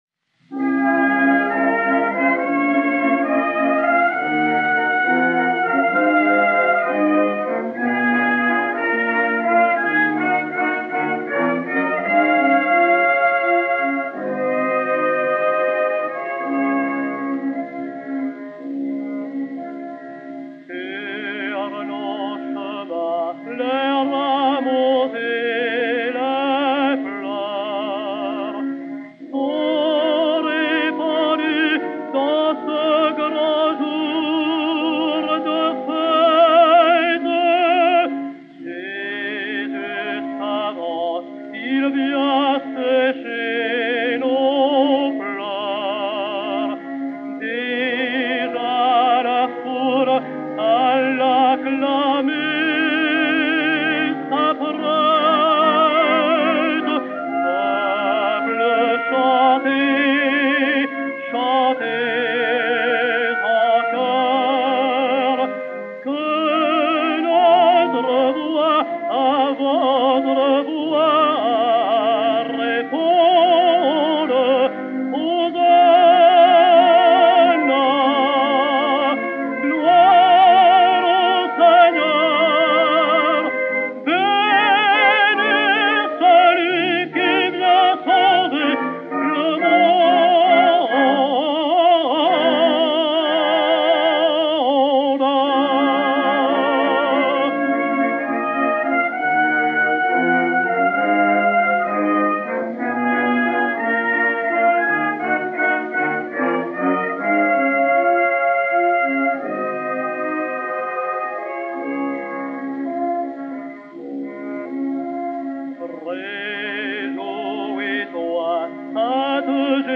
Hymne, poésie de Jules BERTRAND, musique de Jean-Baptiste FAURE (1864).
Edmond Clément et Orchestre Victor dir Rosario Bourdon
C-12999-2, enr. à New York le 17 mars 1913